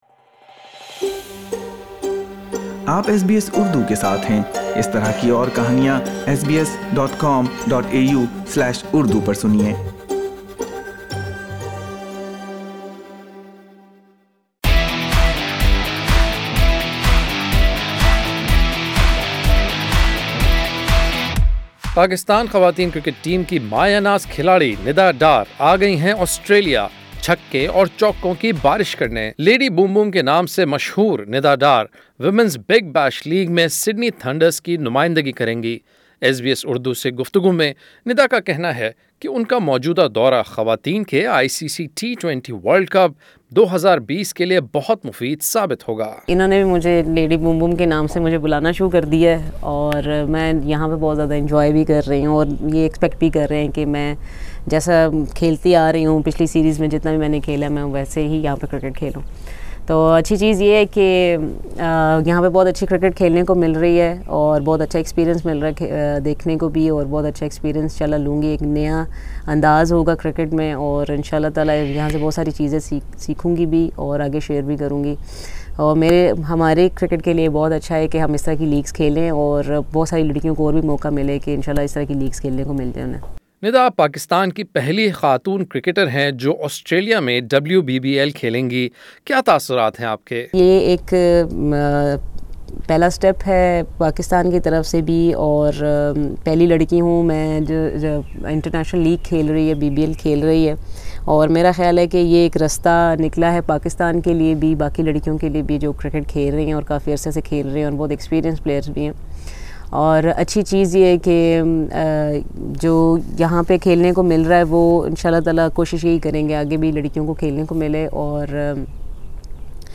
ایس بی ایس اردو نے آنے والے میچز کی تیاری، تیز ٹریکس پر بیٹنگ اور دورہ آسٹریلیا سے متعلق پاکستانی کرکٹر سے سوالات کئے۔